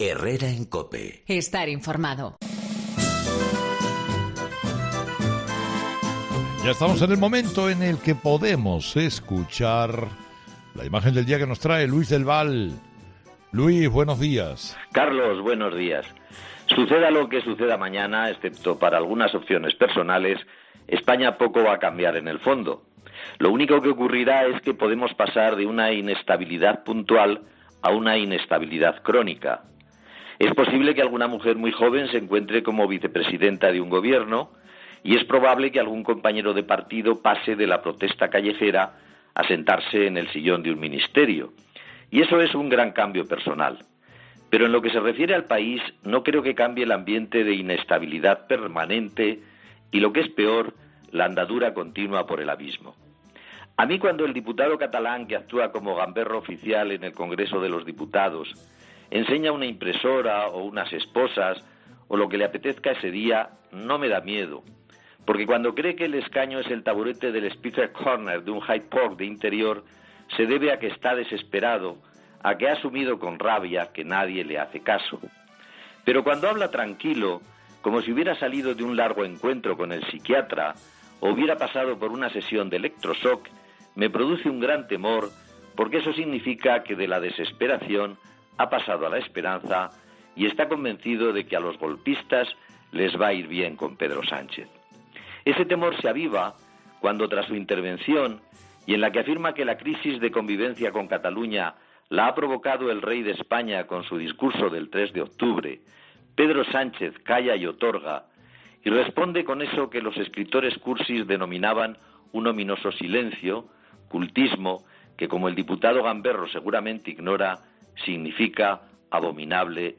El periodista hace un repaso de la sesión de investidura y da su opinión sobre el posible gobierno de coalición entre PSOE y UP